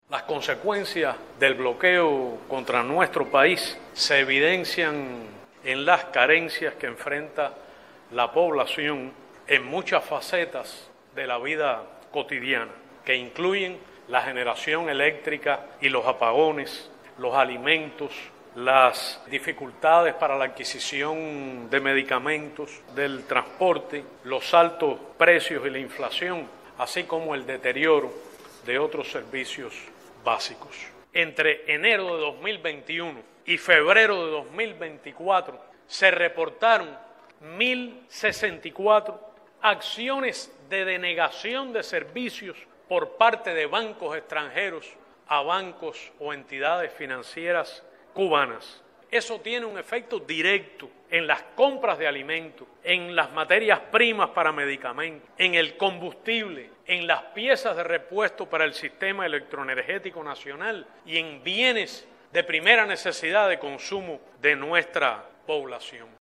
Así lo denunció en conferencia de prensa el ministro de Relaciones Exteriores Bruno Rodríguez Parrilla al presentar, tanto al cuerpo diplomático como a los medios de comunicación nacionales y foráneos, el informe Necesidad de poner fin al bloqueo económico, comercial y financiero impuesto por los Estados Unidos de América contra Cuba.